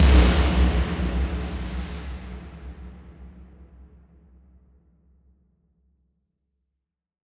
SOUTHSIDE_percussion_and_now_big_drum.wav